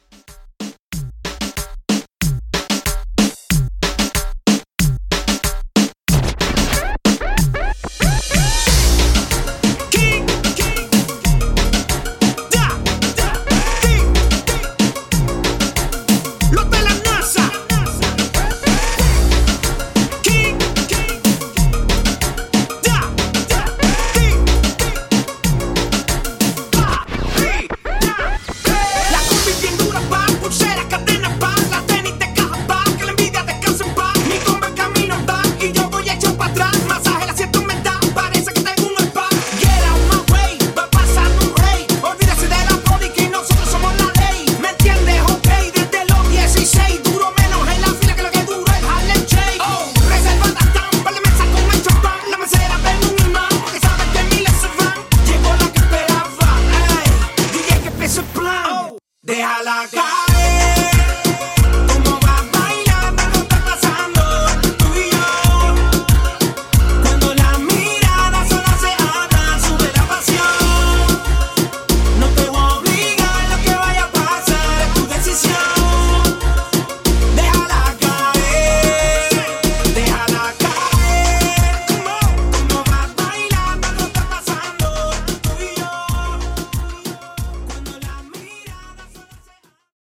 In-Outro Reggaeton)Date Added